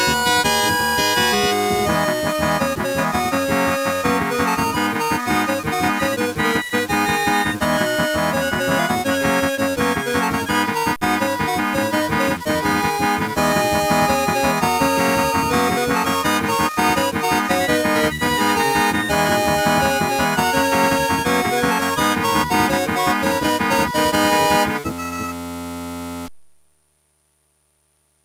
j'avais foiré l'ADSR.